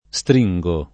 stringare
stringo [ S tr &jg o ], -ghi